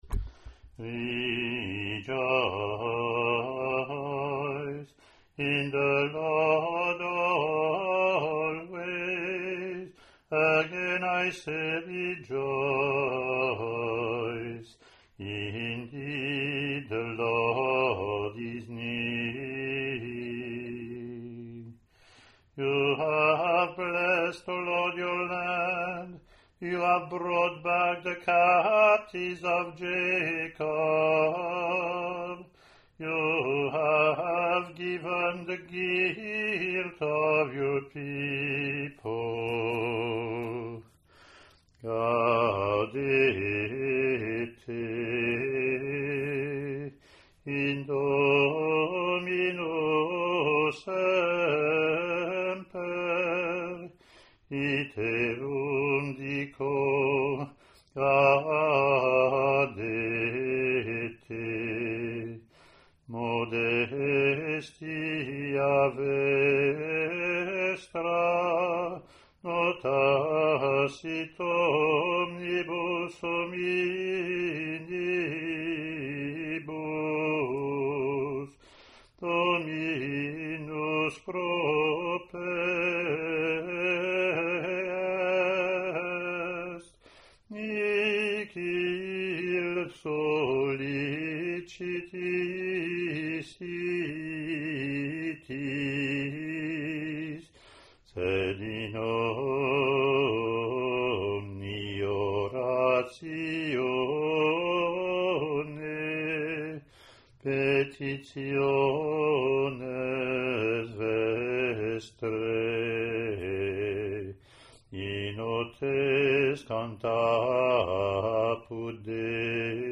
English antiphon – English verse – Latin antiphon and verse)